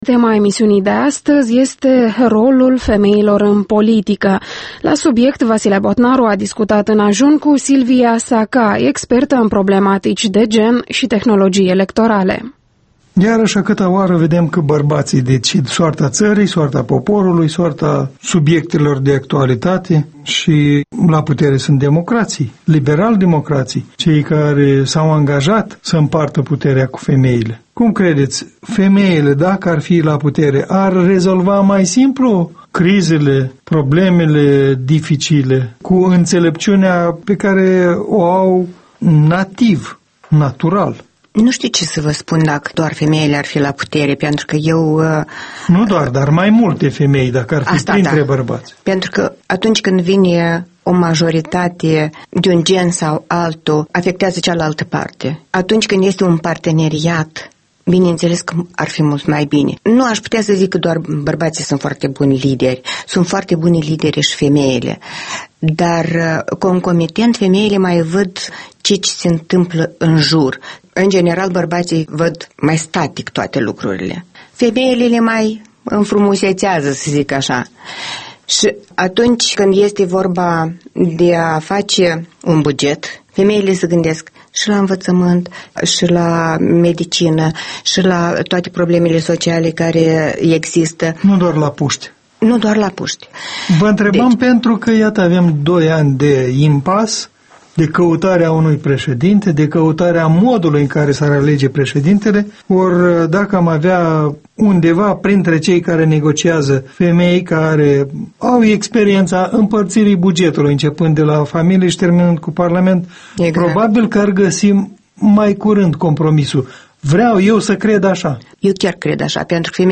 Interviul dimineții la Europa Liberă